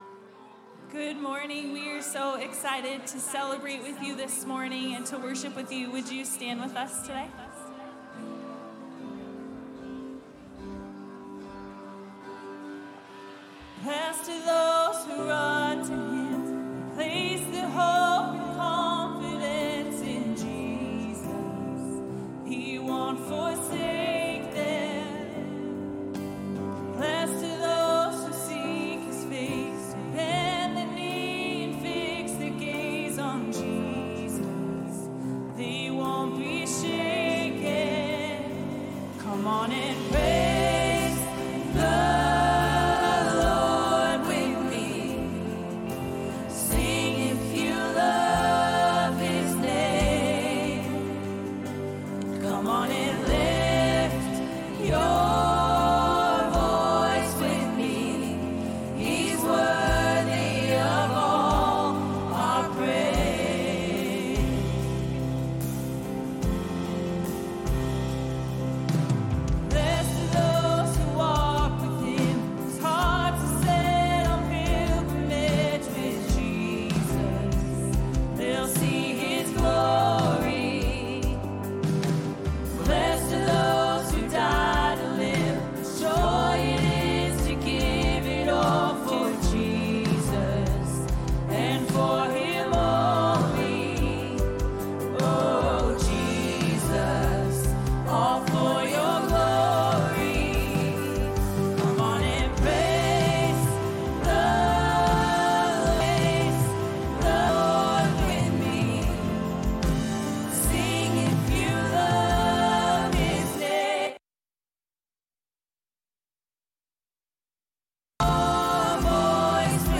Celebration Sunday 2nd service